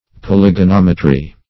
Search Result for " polygonometry" : The Collaborative International Dictionary of English v.0.48: Polygonometry \Pol`y*go*nom"e*try\, n. [Polygon + -metry.] The doctrine of polygons; an extension of some of the principles of trigonometry to the case of polygons.